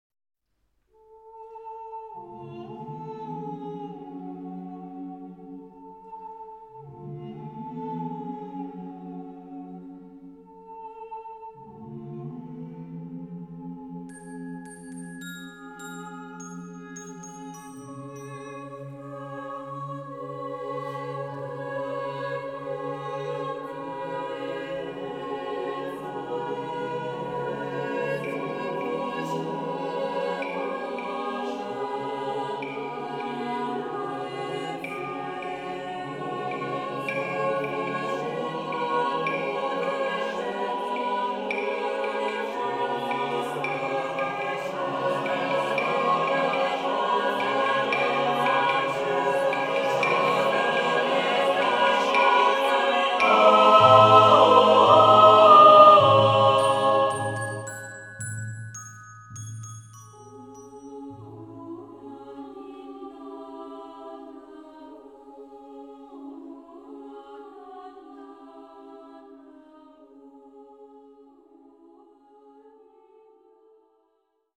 for mixed choir and percussion - italian folksong